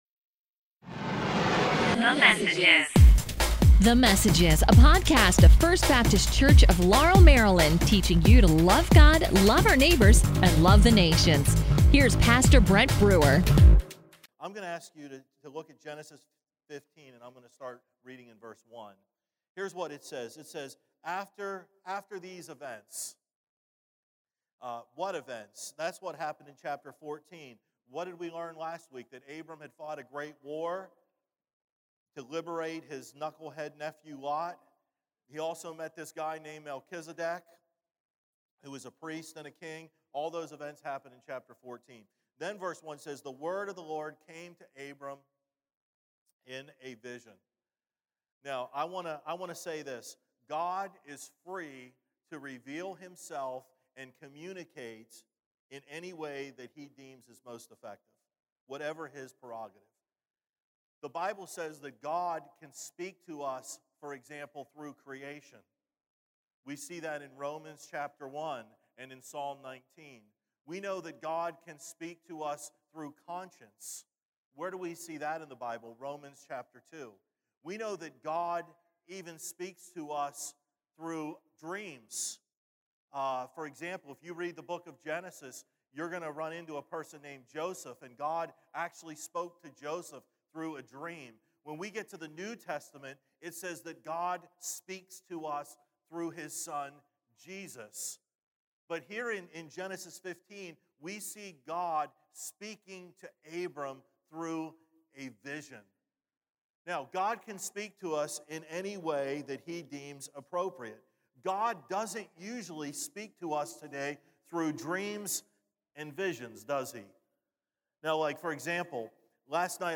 A message from the series "Abraham."